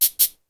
Percs
HOTSHAKE.WAV